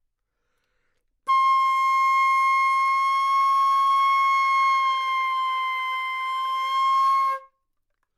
长笛单音（吹得不好） " 长笛C6音准不好
描述：在巴塞罗那Universitat Pompeu Fabra音乐技术集团的goodsounds.org项目的背景下录制。